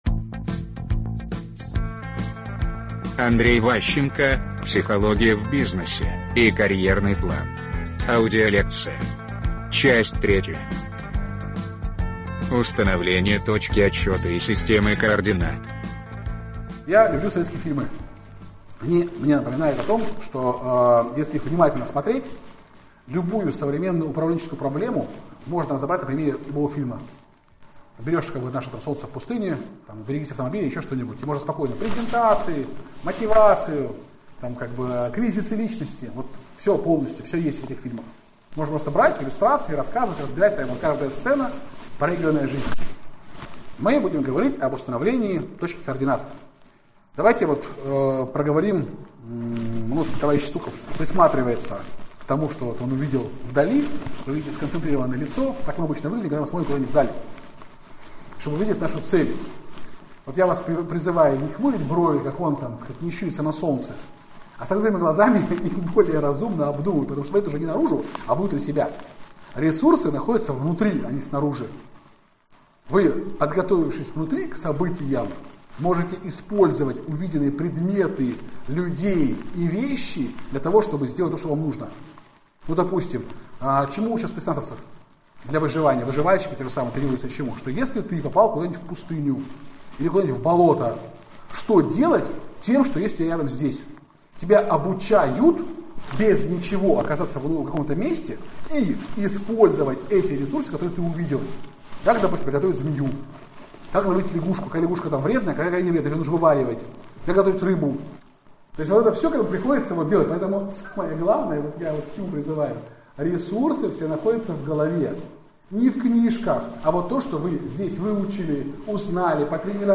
Аудиокнига Психология в бизнесе и карьерный план. Лекция 3 | Библиотека аудиокниг